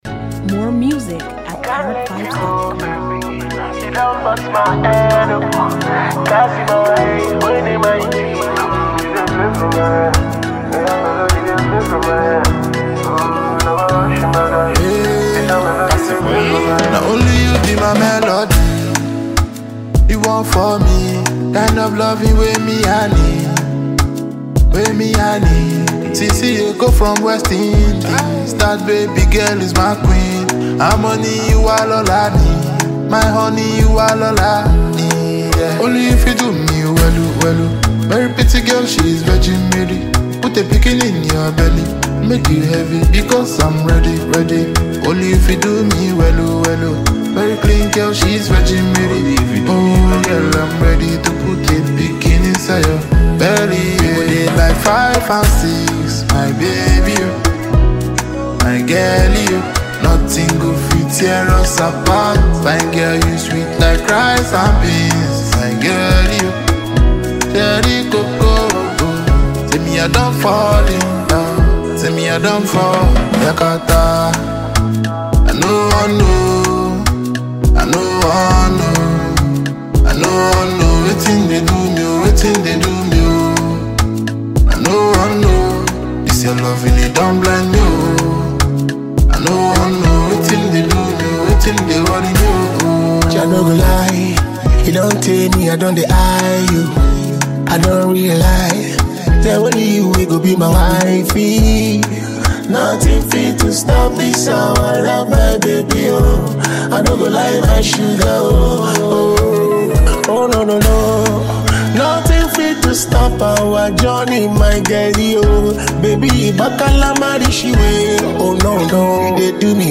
wonderful melodious single